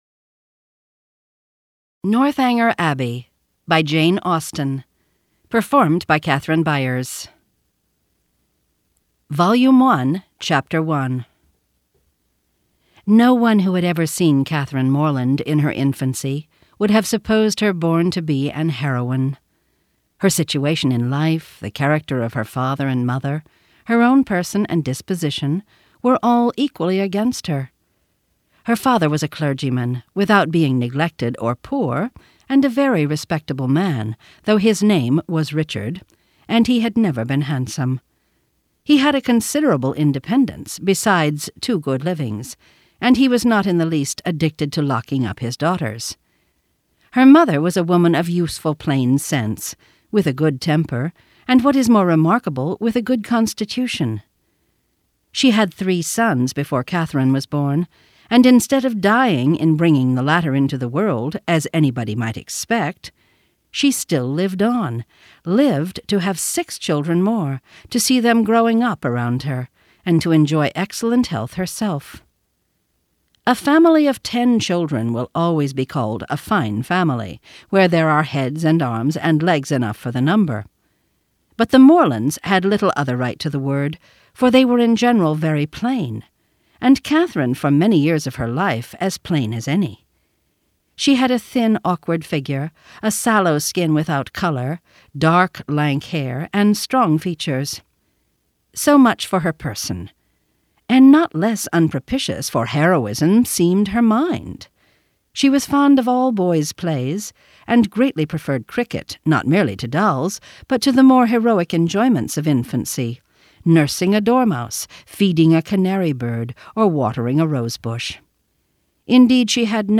Northanger Abbey by Jane Austen, unabridged audiobook mp3 d/l